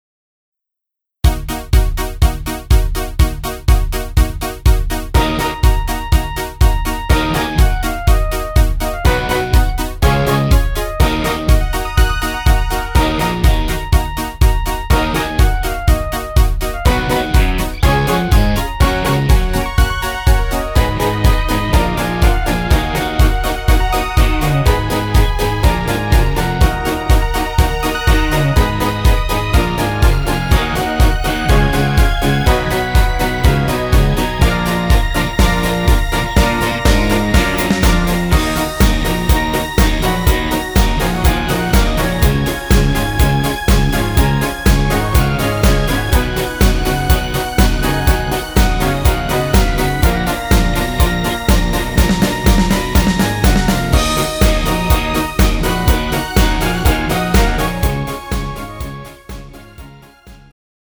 음정 -1키
장르 pop 구분